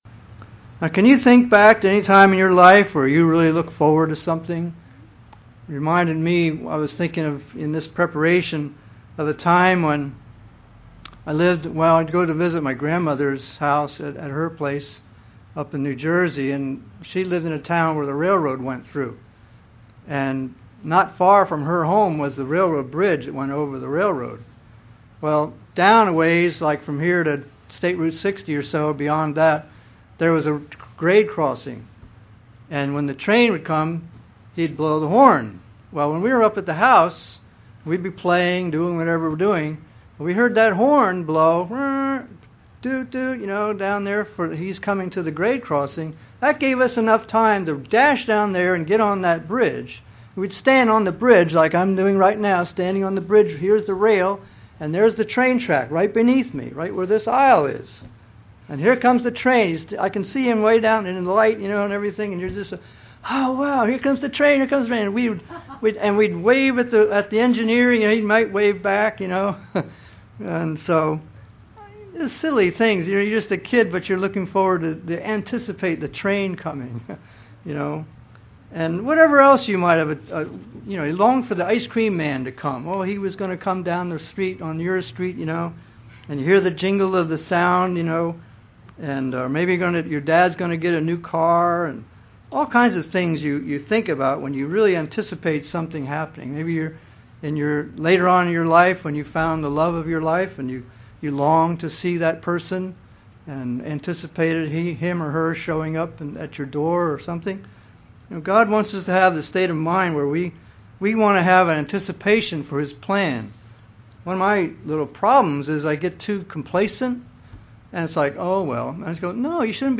Given in Vero Beach, FL
UCG Sermon Studying the bible?